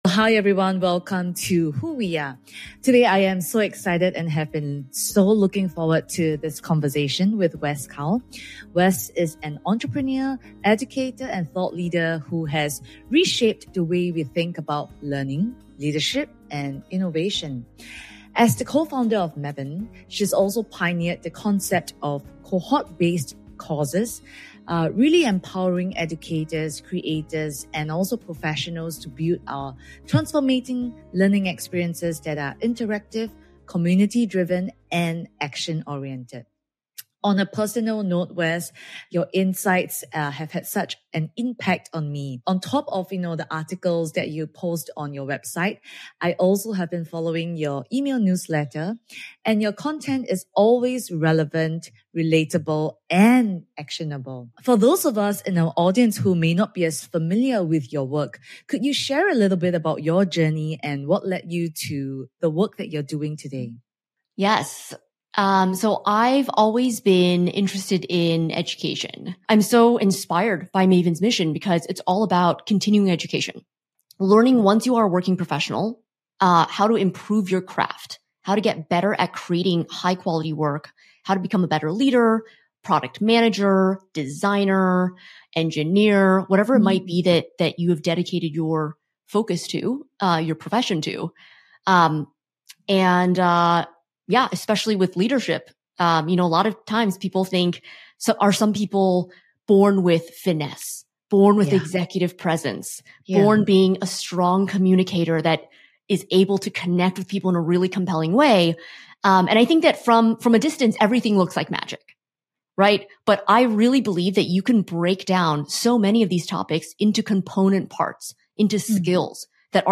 If you're a leader, creator, or communicator of any kind, this conversation is packed with tactical wisdom that will shift how you speak, lead, and show up.